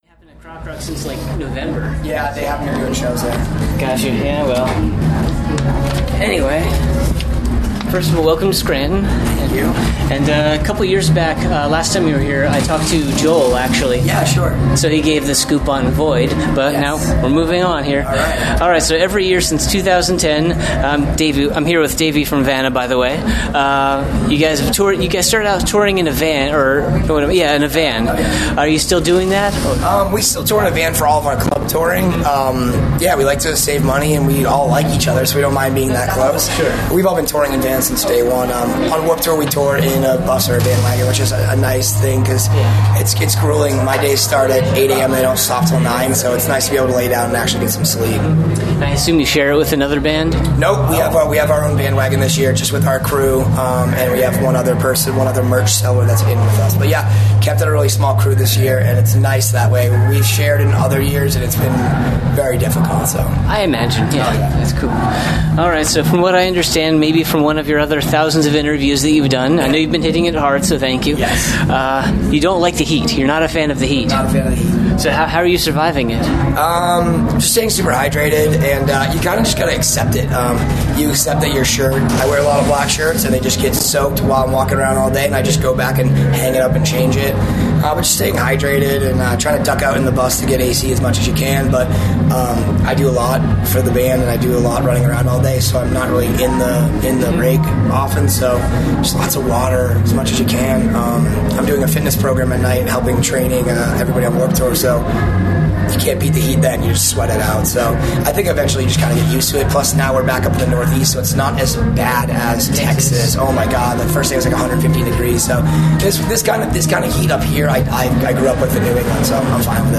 Despite this, he is a really friendly and talkative fellow and there was plenty to talk about.
70-interview-vanna.mp3